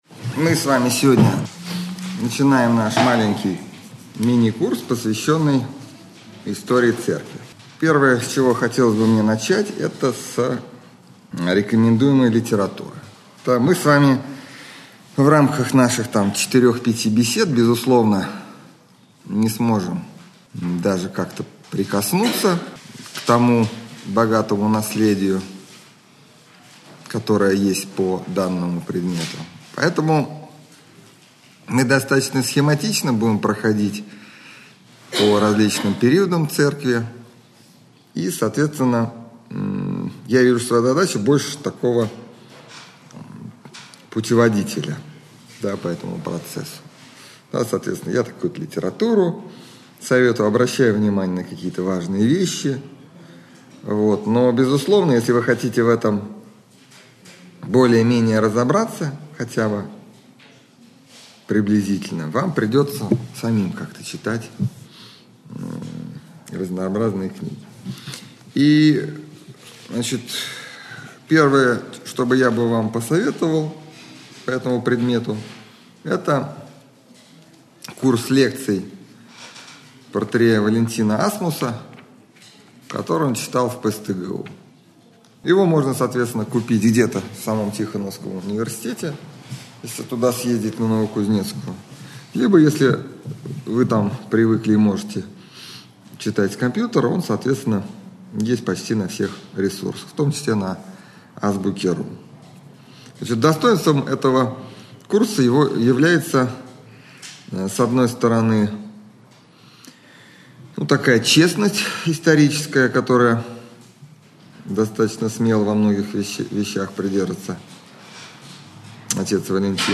лекция